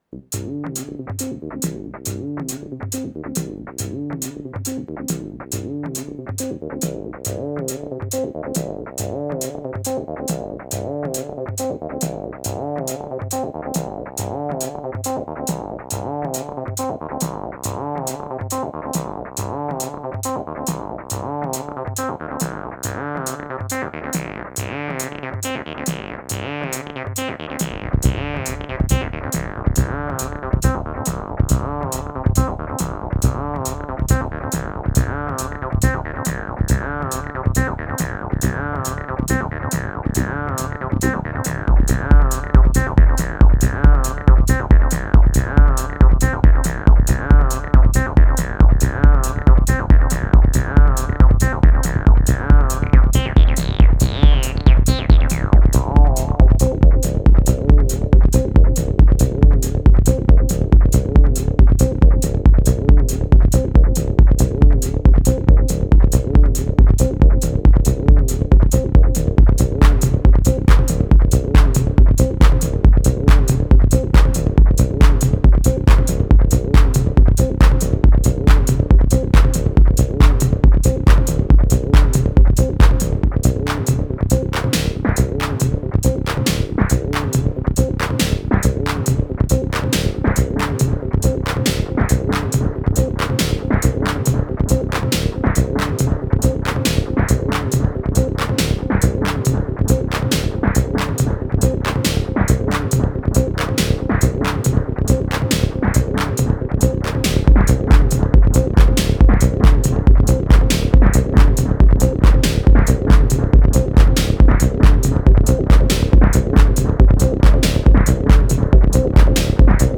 automatic musicians